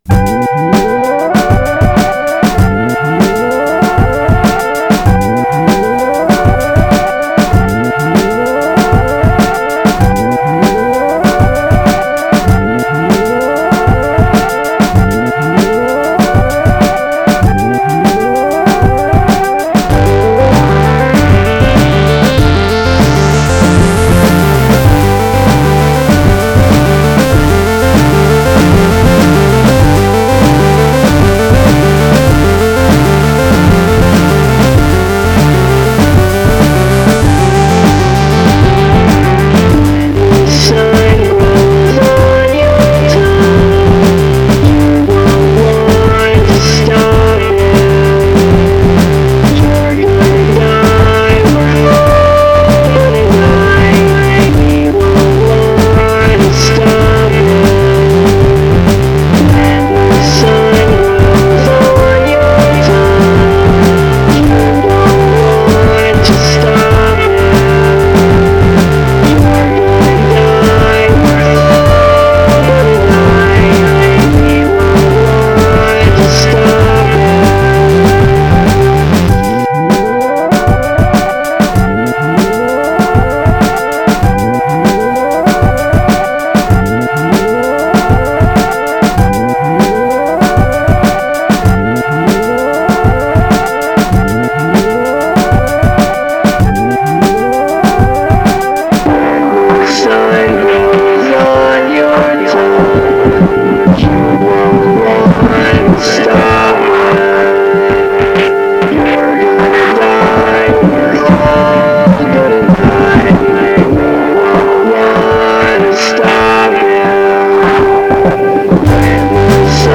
trippy, psychedelic and highly melodic songs
Weird, fun, kid friendly… and its got a great beat!